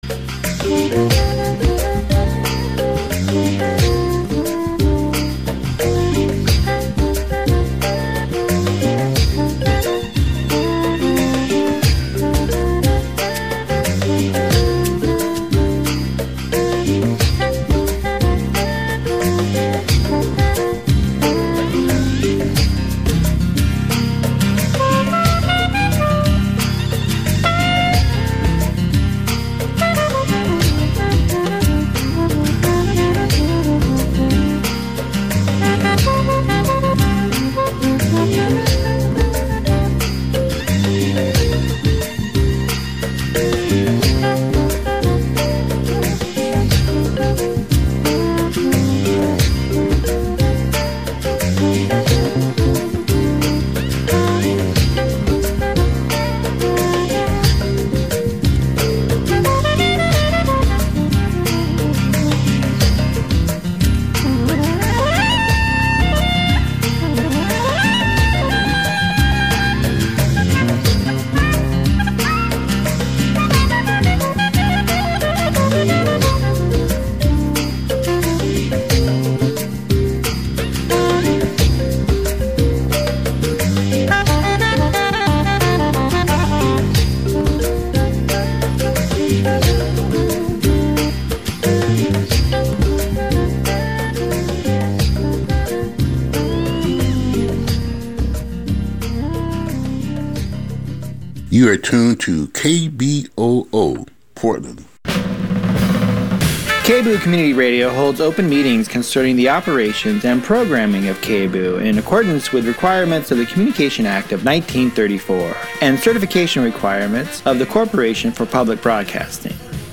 Old Mole Variety Hour for July 28, 2025 | KBOO Listen Now On Air: Democracy Now!